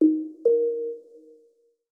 Simple Digital Connection 8.wav